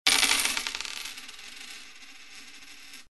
На этой странице собраны разнообразные звуки монет: от звонкого падения одиночной монеты до гула пересыпающихся денежных масс.
Звук монеты кинули на стол